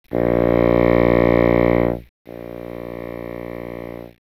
The were calculated assuming the listener and the bassoon were in a room measuring 10m x 10m x 25m.
The direct signals from the bassoon, 5m away, then 15m away